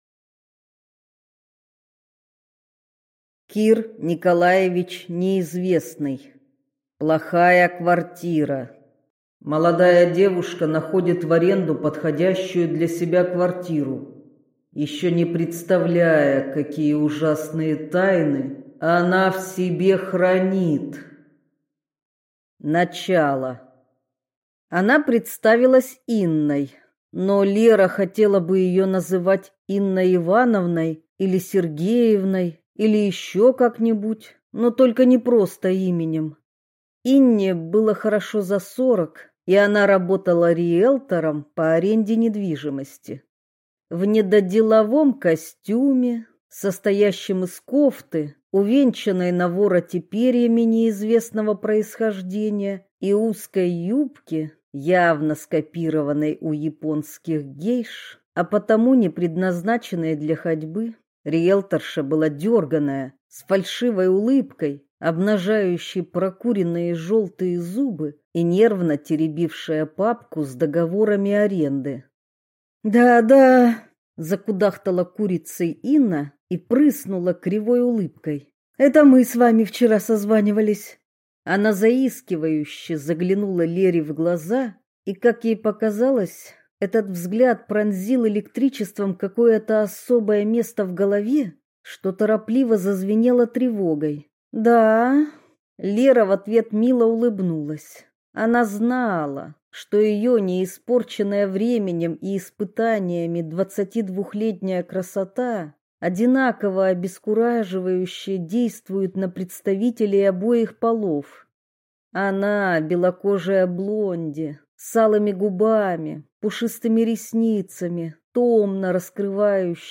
Аудиокнига Плохая квартира